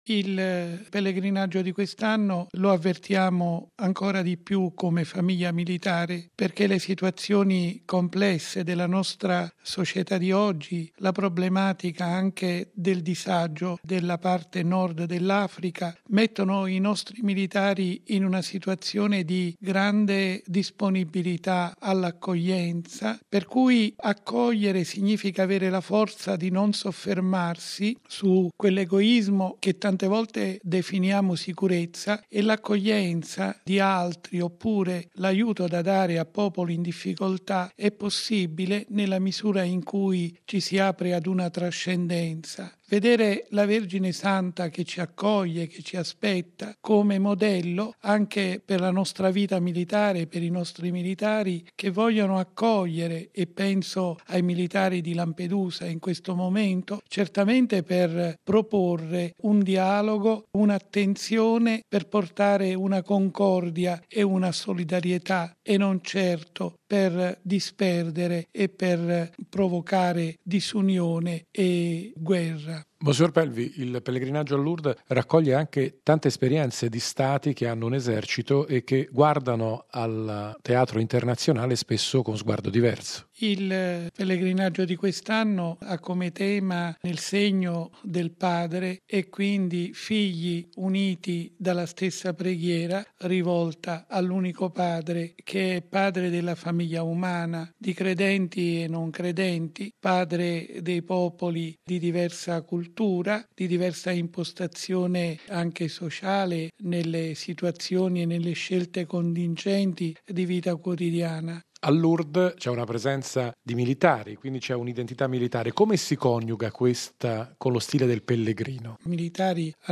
Al pellegrinaggio prendono parte più di 3.500 soldati italiani, e anche una trentina di parenti dei caduti nelle missioni all’estero degli ultimi anni. Ad accompagnare la rappresentanza italiana è l’arcivescovo Vincenzo Pelvi, ordinario militare per l’Italia